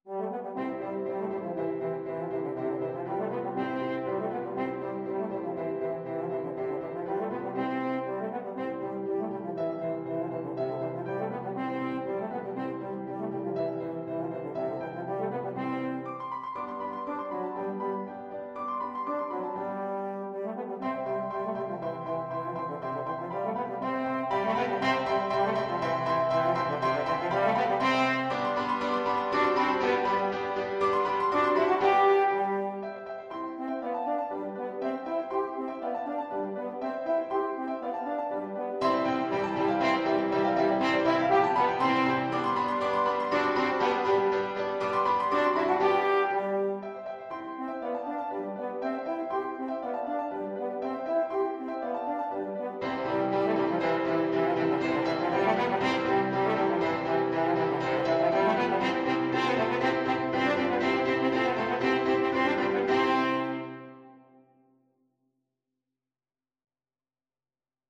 Bacchus lebe! from The Abduction from the Seraglio French Horn version
2/4 (View more 2/4 Music)
C major (Sounding Pitch) G major (French Horn in F) (View more C major Music for French Horn )
Classical (View more Classical French Horn Music)